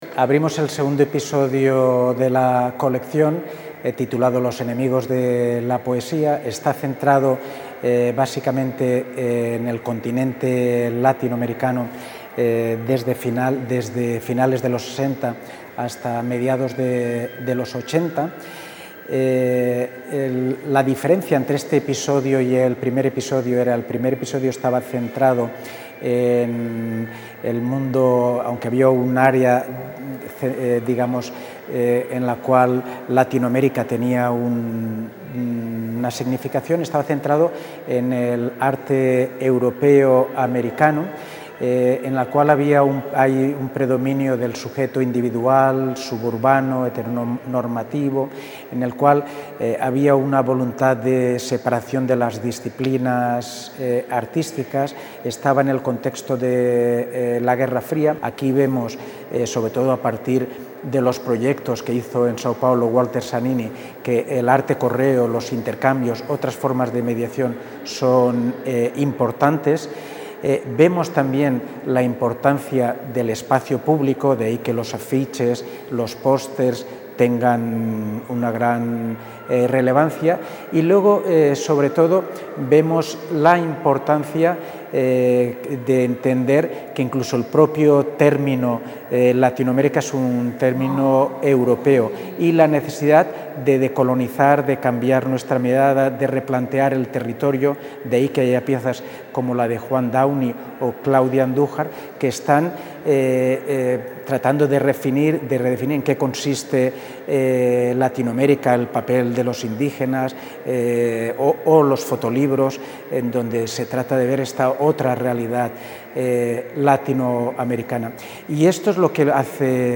Declaraciones del director del Museo, Manuel Borja-Villel (.mp3 6 MB)
declaraciones_manuel_borja-villel_-_coleccion_episodio_2.mp3